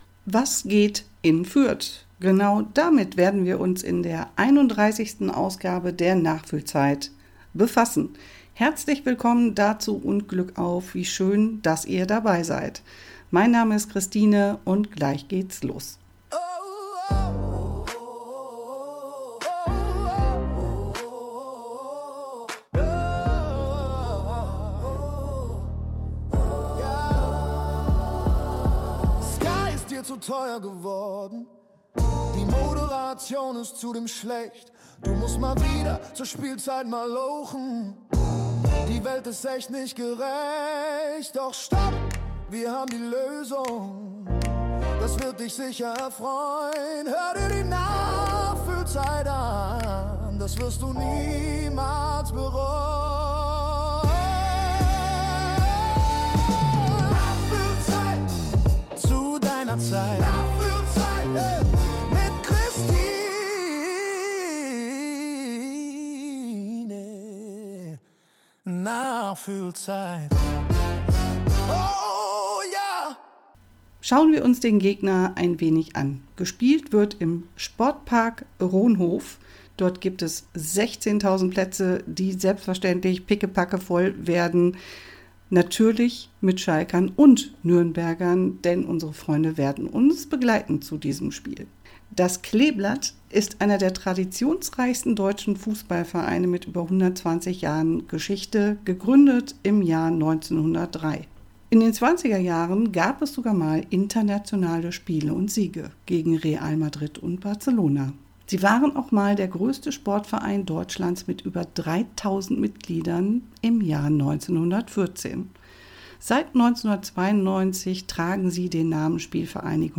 Schalke Audio Re-Live vom 28.02.2026